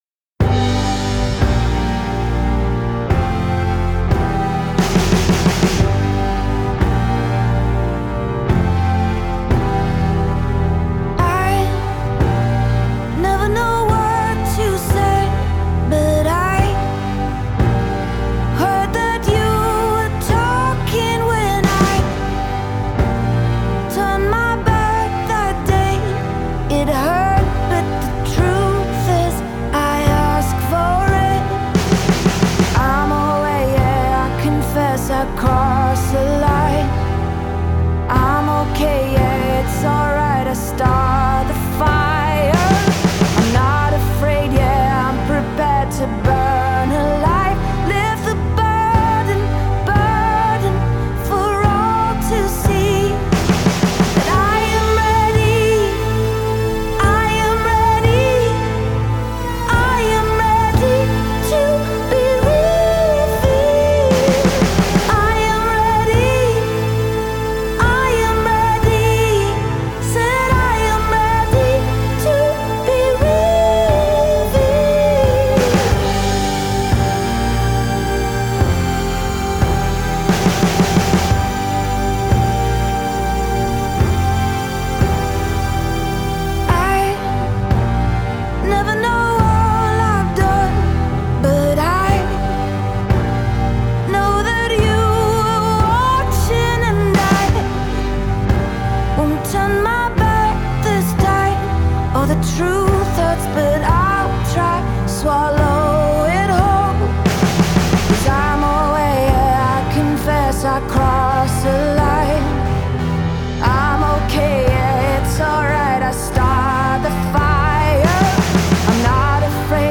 Жанр: pop · female vocalists · indie · singer-songwriter